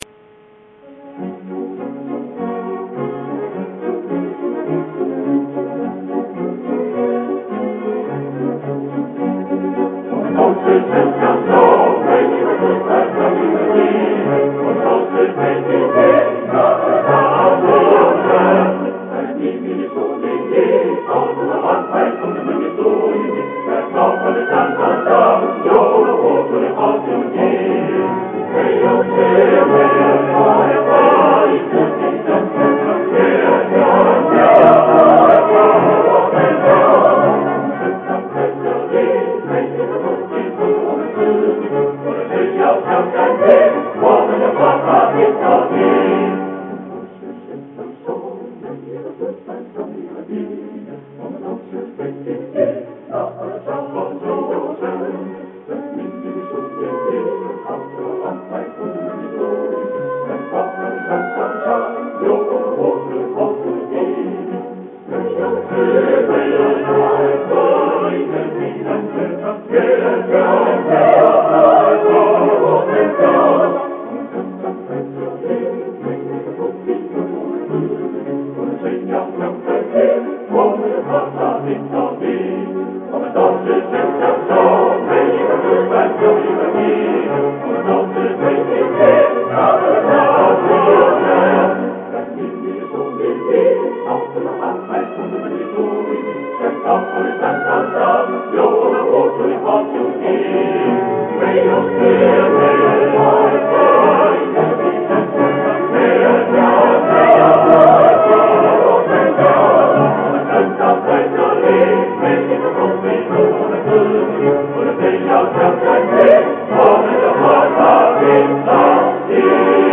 Описание: Китайская песня о партизанской борьбе, хор